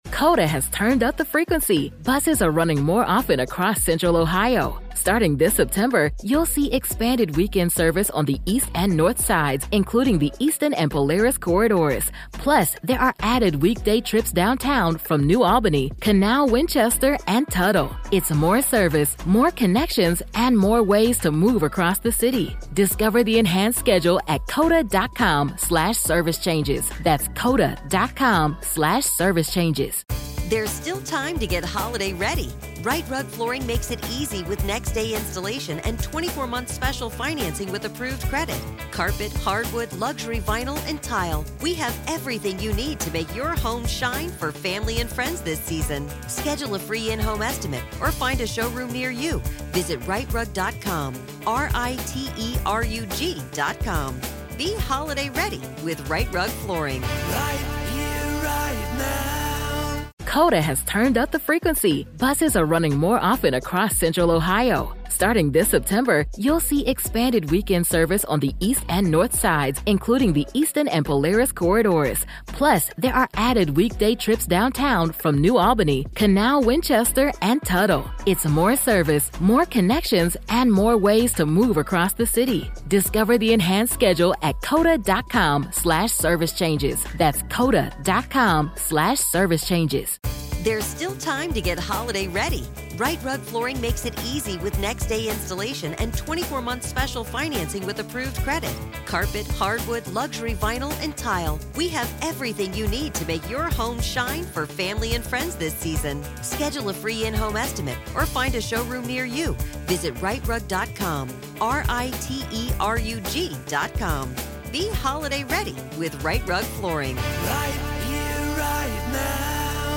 Her story offers not only hope and healing, but also a glimpse into the mysteries of energy, spirit communication, and the unseen forces that shape our lives. This is Part Two of our conversation.